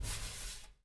LQB SWISHD-R.wav